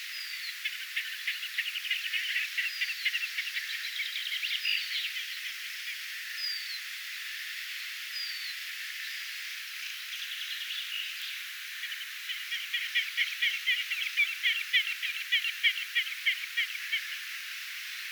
tupsu-uikkuparin ääntelyä
tupsu-uikun_aantelya_kaksi_aantelya_ehka_parin_yhtaaikaista_aantelya.mp3